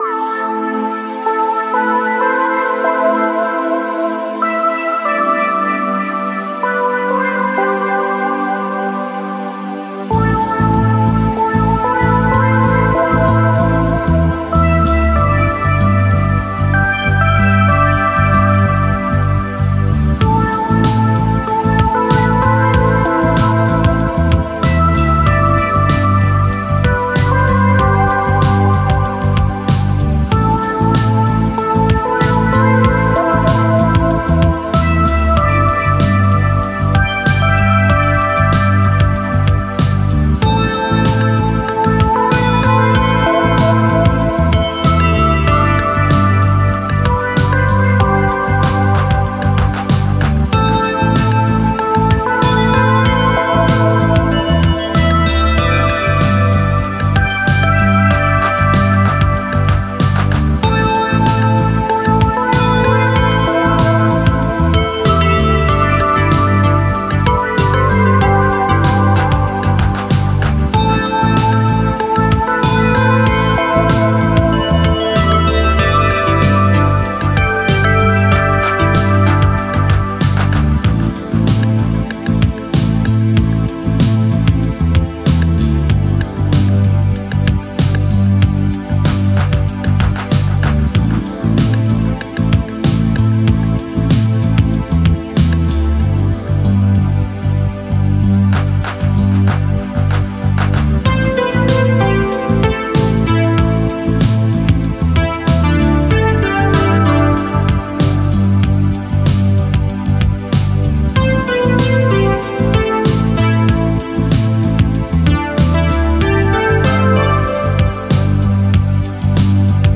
Synthwave, Retrowave, Spacewave, Dreamwave, Electronic